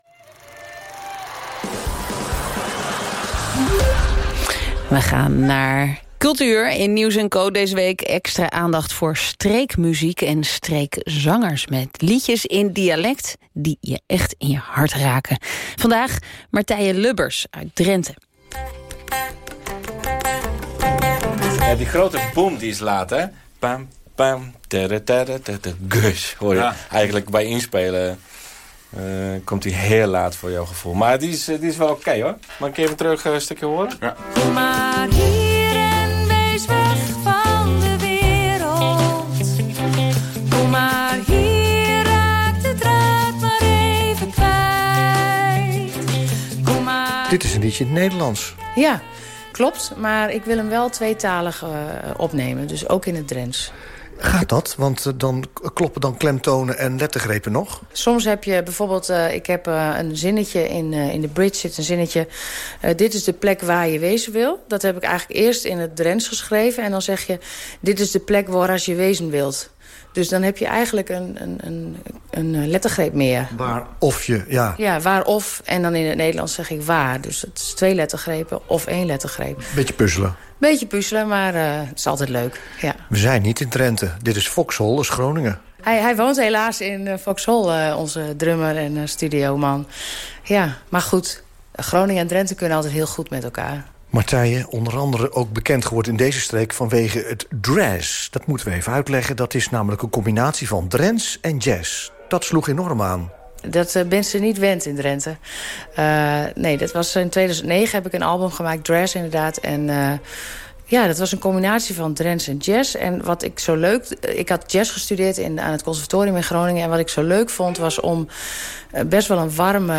Reportage op Radio 1 - Nieuws en Co